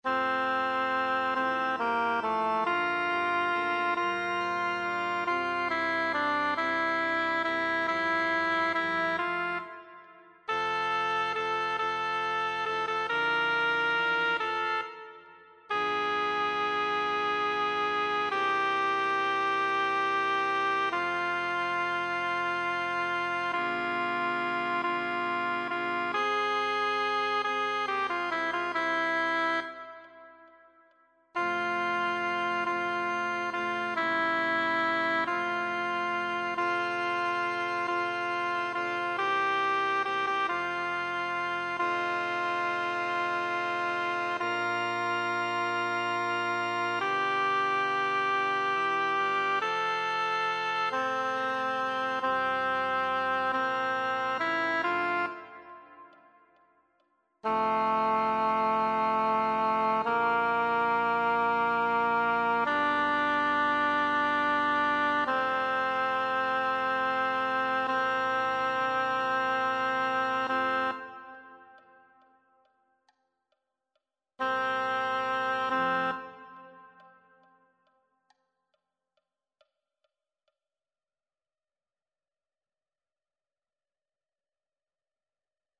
Singwoche pro Musica 2026 - Noten und Übungsdateien
Alt